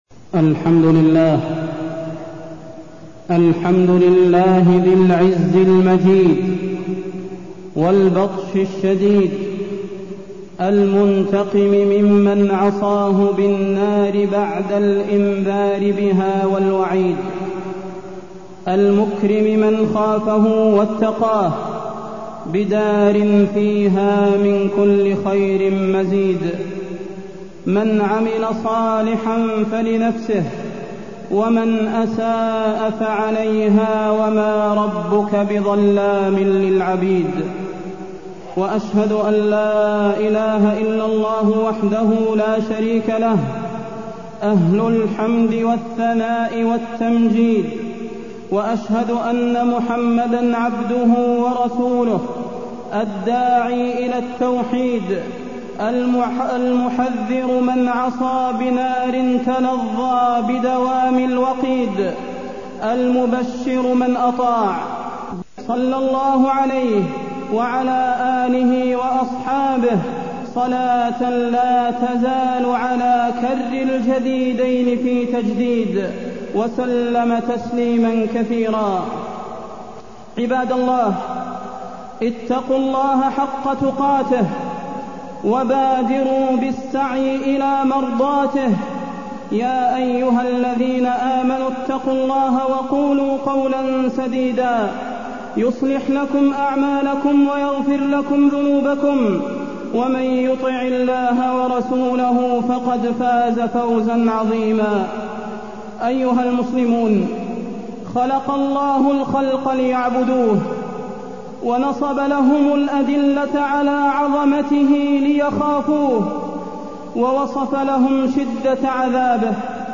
تاريخ النشر ١٨ جمادى الأولى ١٤٢١ هـ المكان: المسجد النبوي الشيخ: فضيلة الشيخ د. صلاح بن محمد البدير فضيلة الشيخ د. صلاح بن محمد البدير النار وأهوالها